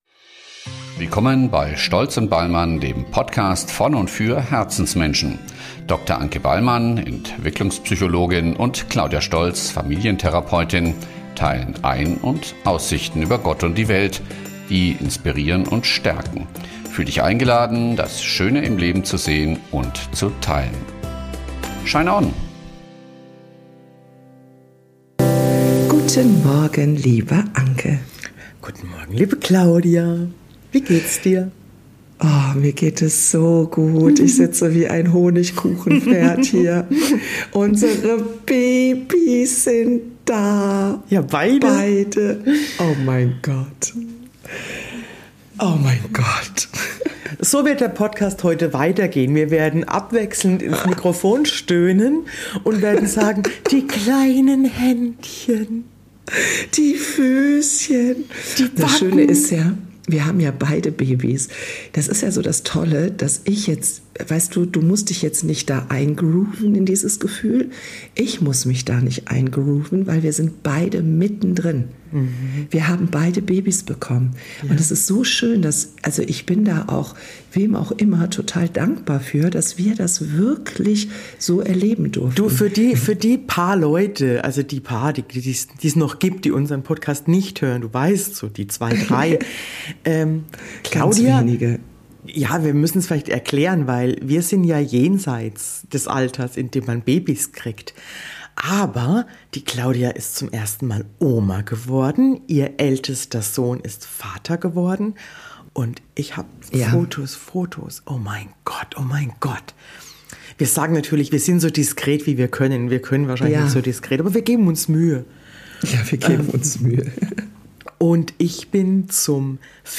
kommentiert mit charmantem Schluckauf die Tragetuchgeschichten der Shinieschwestern